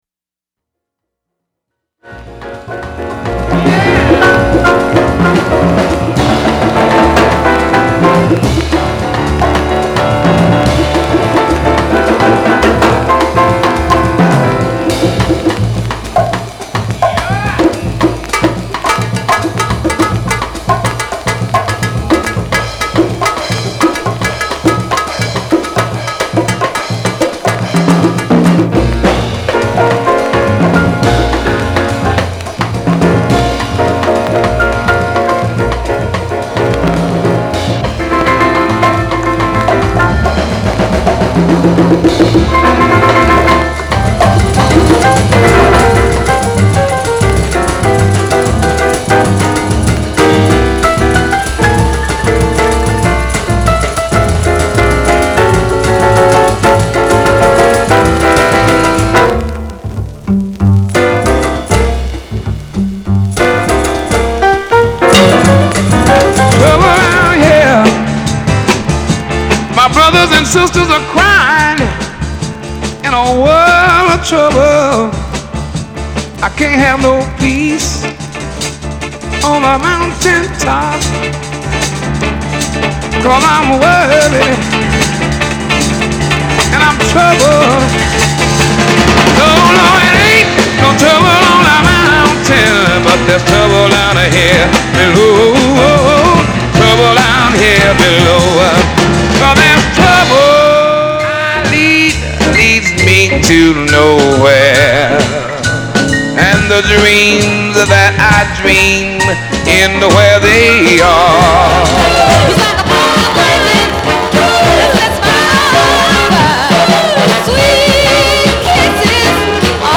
R&B、ソウル
/盤質/両面全体に傷あり/US PRESS